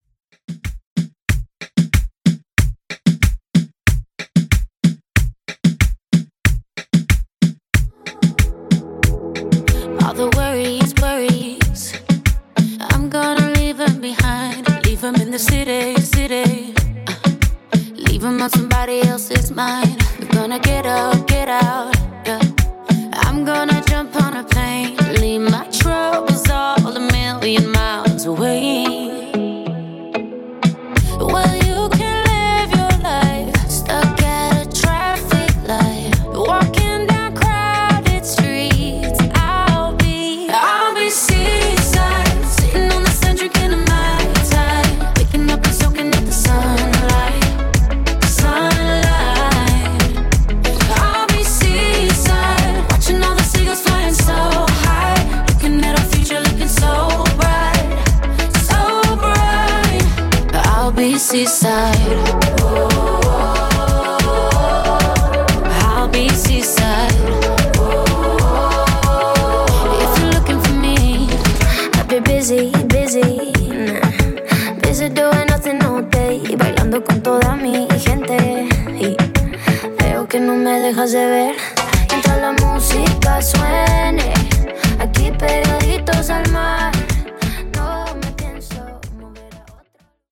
Reggaeton)Date Added